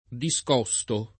[ di S k 0S to ]